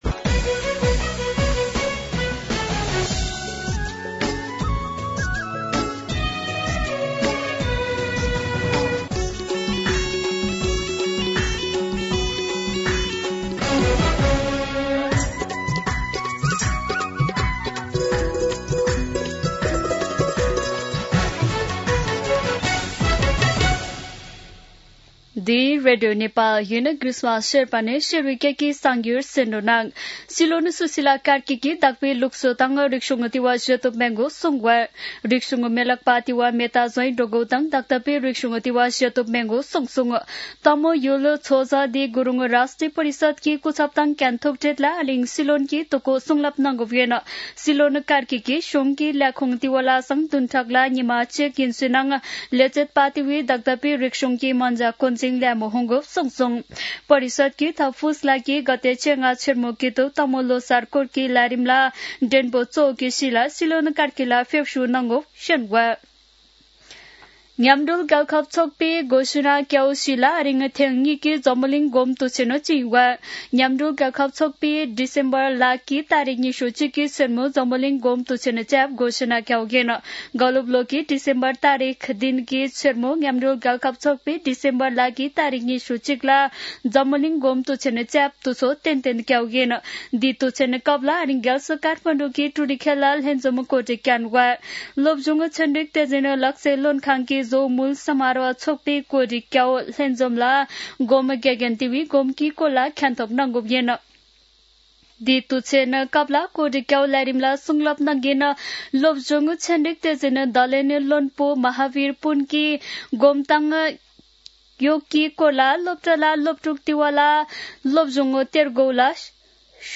शेर्पा भाषाको समाचार : ६ पुष , २०८२
Sherpa-News-9-6-.mp3